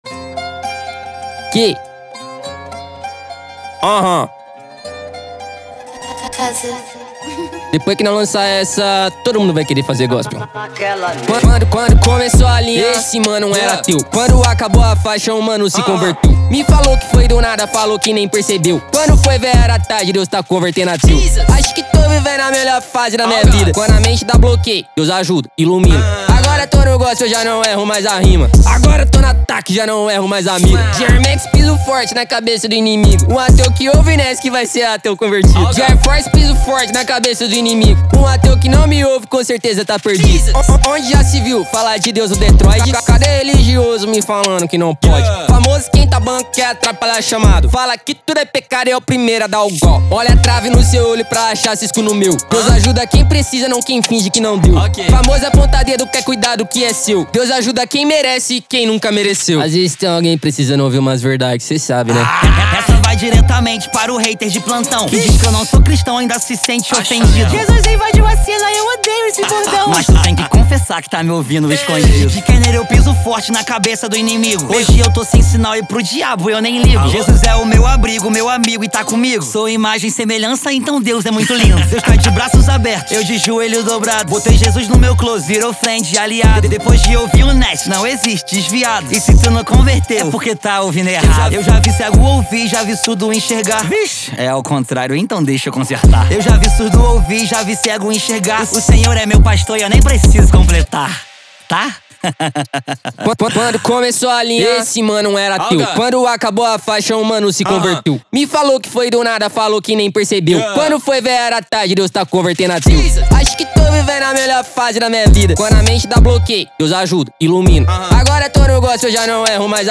2024-05-05 18:18:26 Gênero: Rap Views